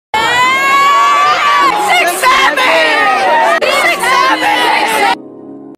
67 sound Meme Sound Effect
This sound is perfect for adding humor, surprise, or dramatic timing to your content.